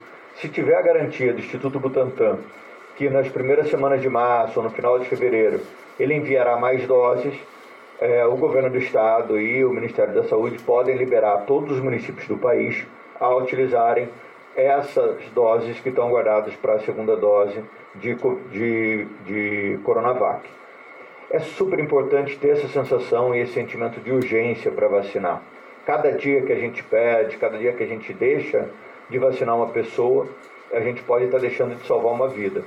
Son: Eduardo Paes